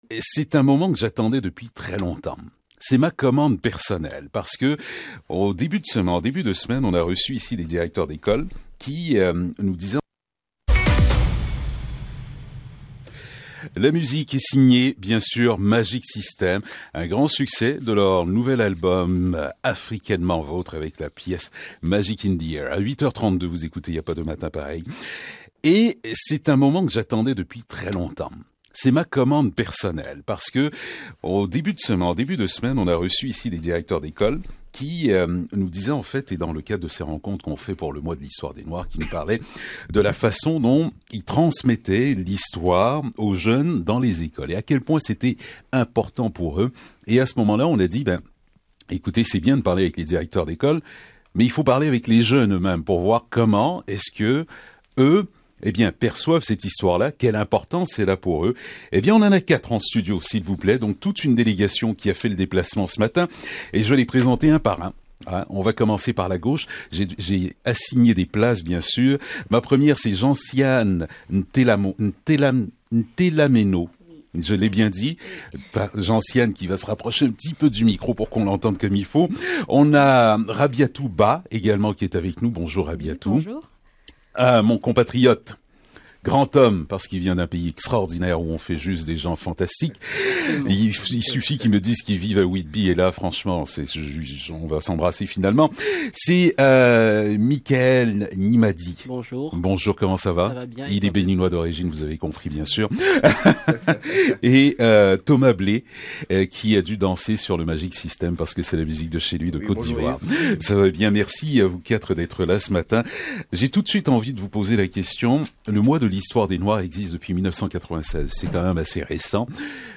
On donne la parole directement aux jeunes pour voir comment ils se sentent face au Mois de l’histoire des Noirs.